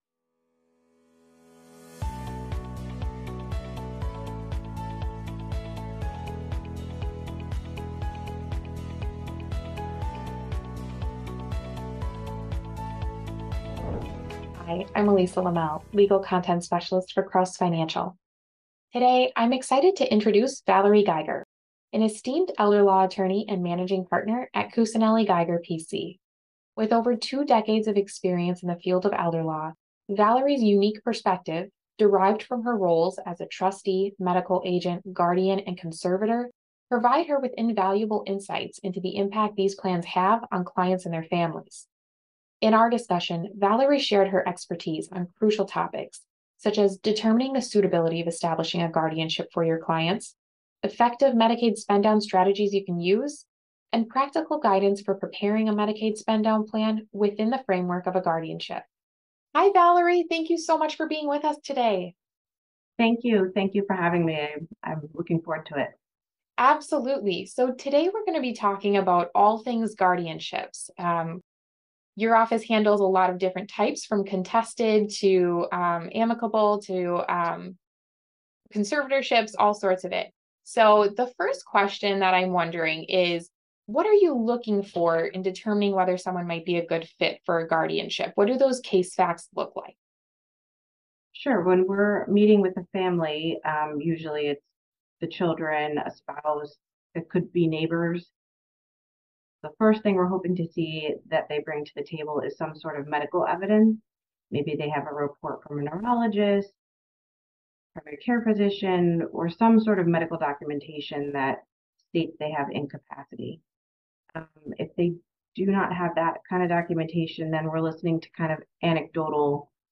Learn effective Medicaid spend-down strategies and the suitability of a guardianship for your client in this Elder Law Interview